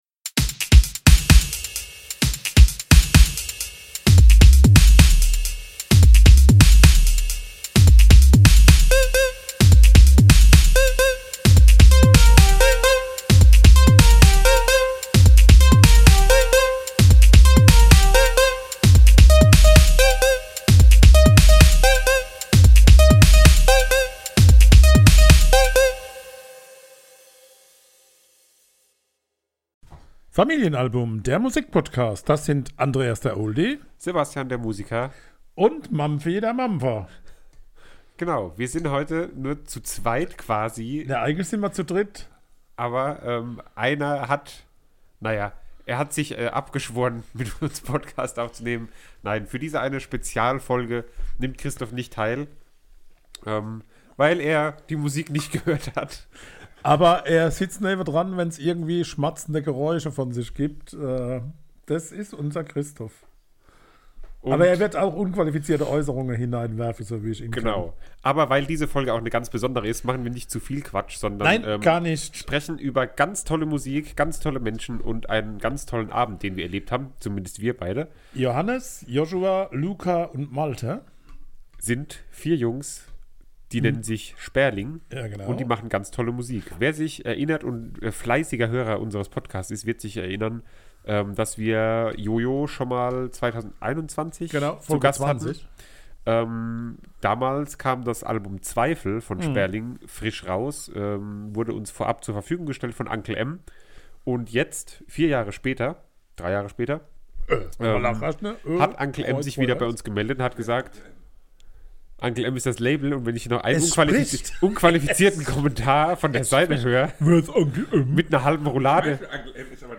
Wir durften zu Sperling in die Batschkapp in Frankfurt!
Konzertbericht Mehr